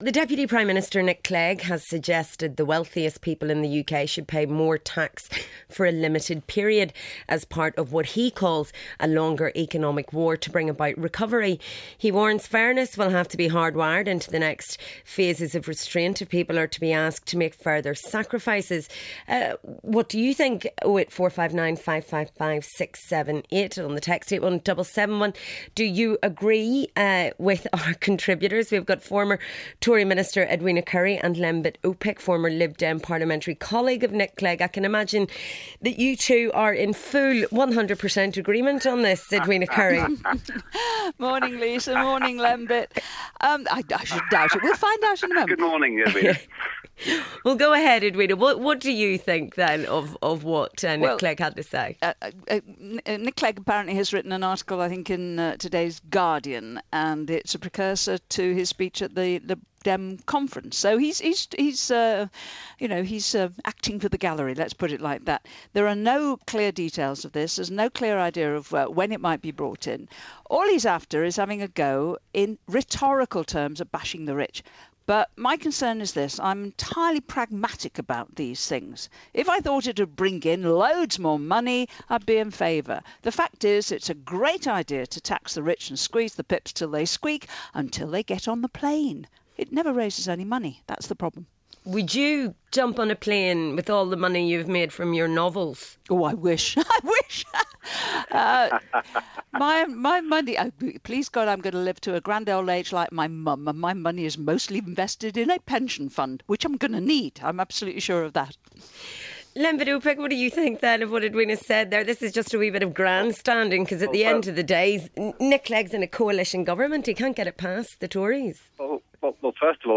The Deputy Prime Minister, Nick Clegg, has suggested that the wealthiest people in the UK should pay more tax for a limited period, as part of what he calls a longer economic war to bring about recovery. He warns that fairness will have to be hard-wired into the next phases of restraint - if people are to be asked to make further sacrifices. former Tory politician Edwina Currie and former Lib Dem Lembet Opik discuss the Deputy Prime Minister's comments.